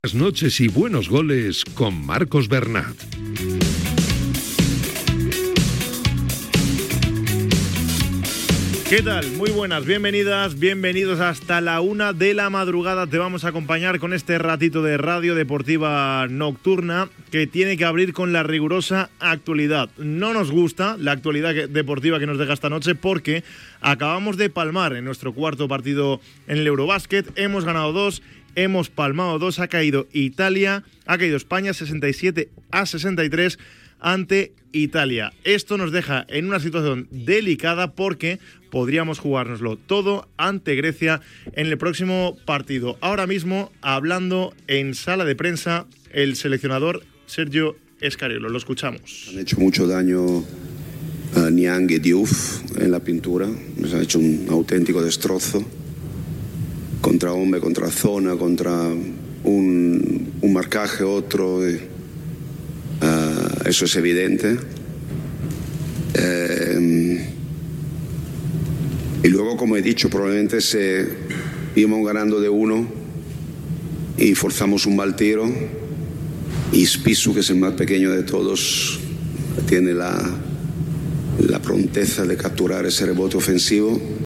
Careta, inici del programa després de la derrota de la selecció espanyola masculina de bàsquet. Parla el seleccionador Sergio Scariolo.
Esportiu